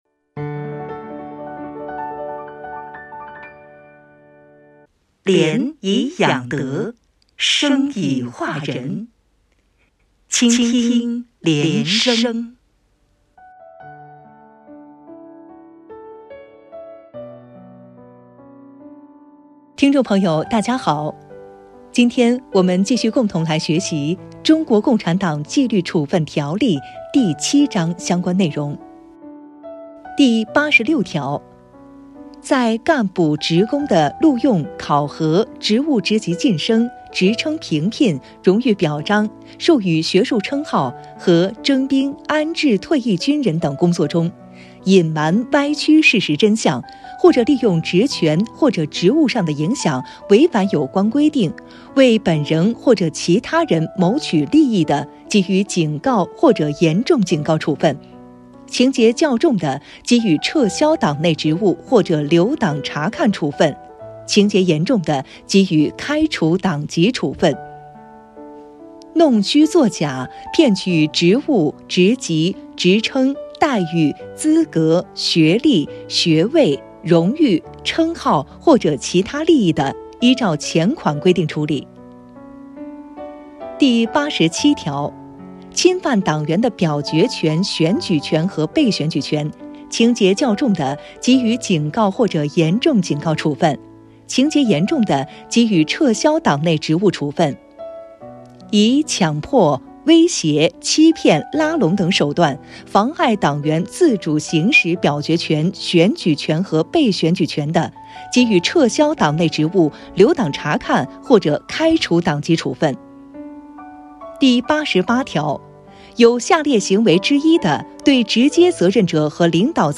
原文诵读系列音频